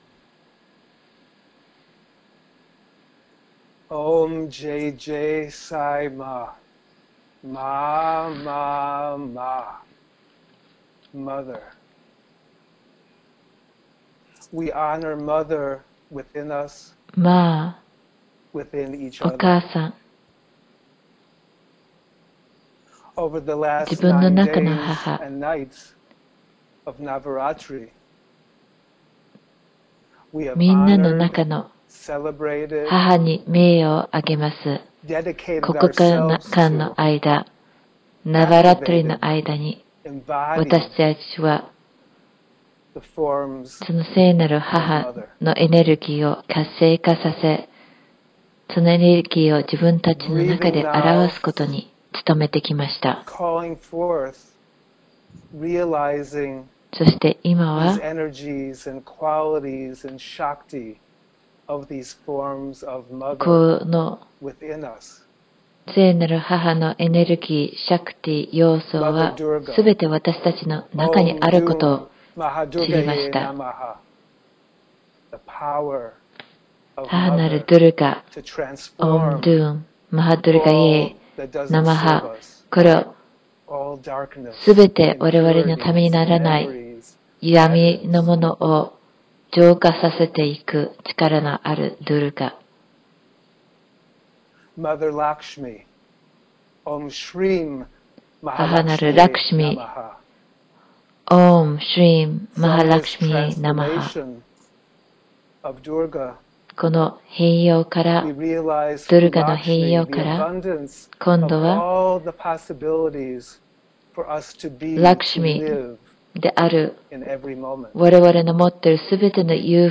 ヴィクトリーデイの瞑想.mp3